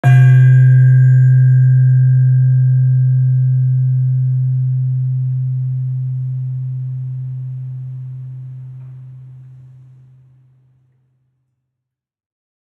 HSS-Gamelan-1 / Gender-2